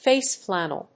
アクセントfáce flànnel